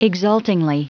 Prononciation du mot exultingly en anglais (fichier audio)